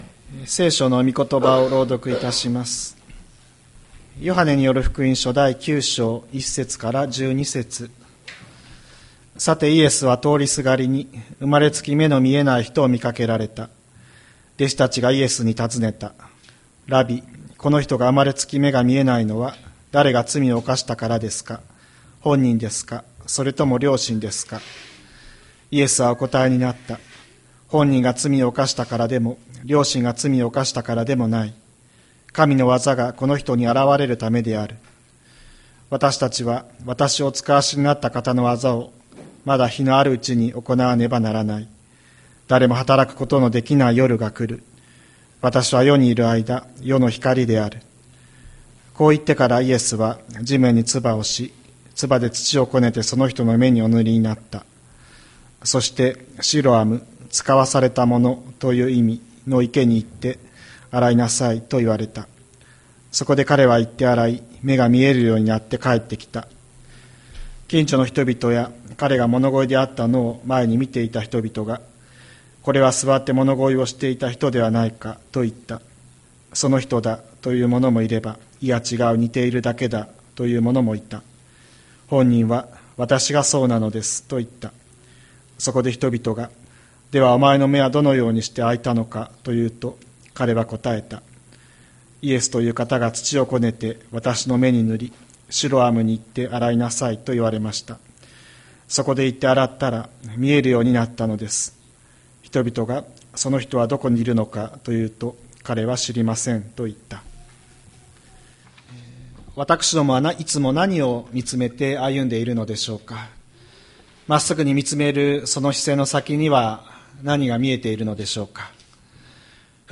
千里山教会 2025年05月11日の礼拝メッセージ。